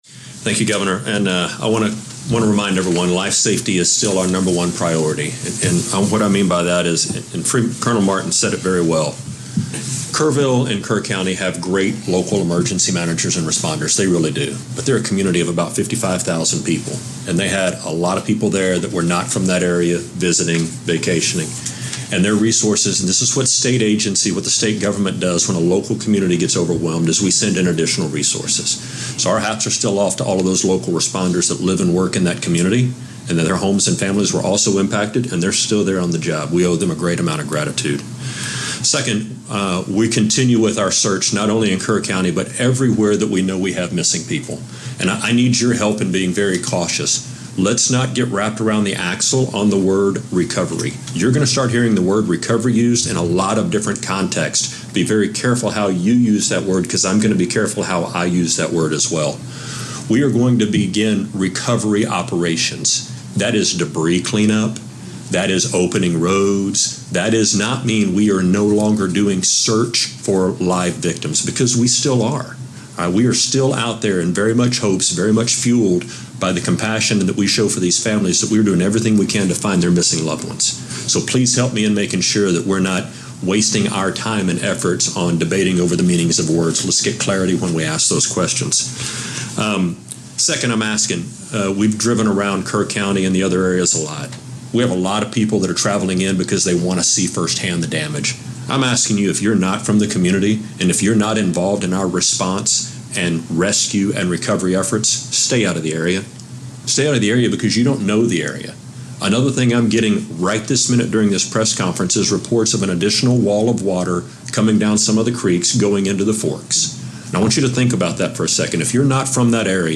TDEM Chief Nim Kidd
delivered 6 July 2025, Austin, Texas
Audio Note: AR-XE = American Rhetoric Extreme Enhancement